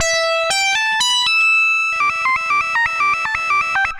Index of /musicradar/80s-heat-samples/120bpm
AM_CopMono_120-E.wav